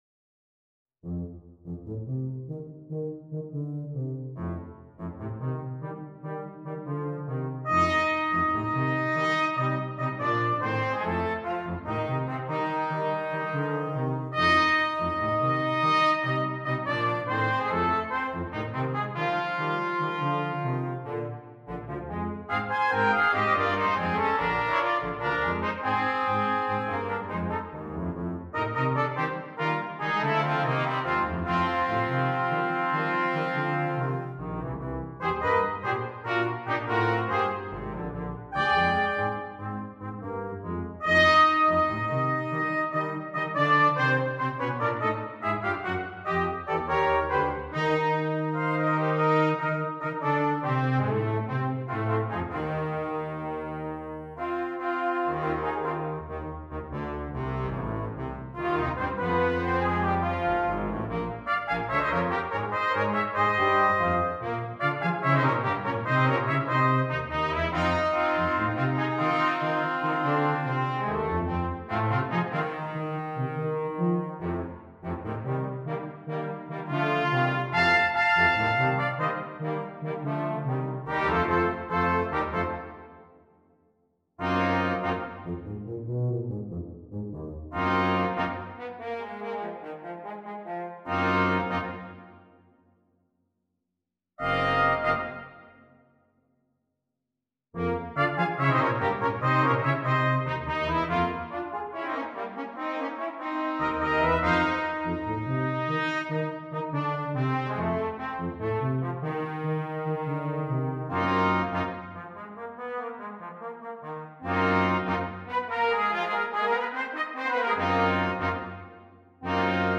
Brass Quintet (optional Drum Set)
Drum part included with fills written out.